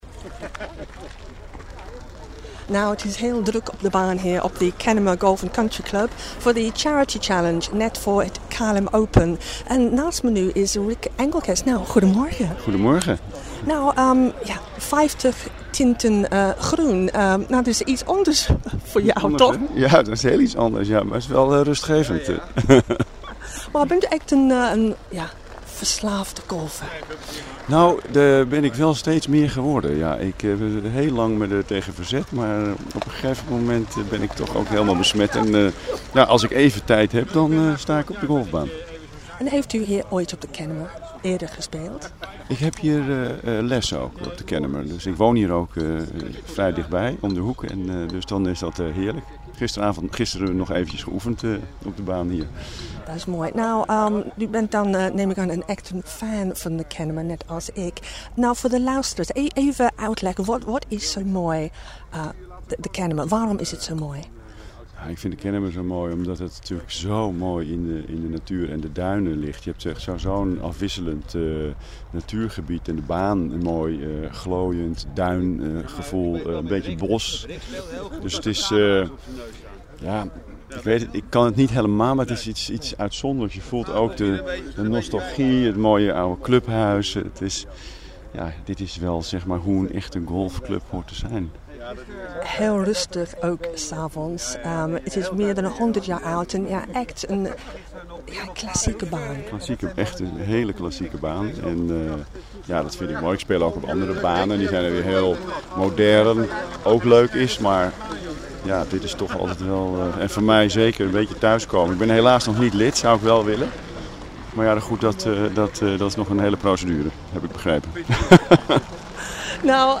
DUTCH radio interview 2 min 32 sec.